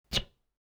fb03f163db Bildschirmflausch-LD41 / Assets / sound / attack.wav Unknown 2bd1b8b886 added some of the sound effects + go bgm 2018-04-22 23:34:09 +02:00 223 KiB Raw History
attack.wav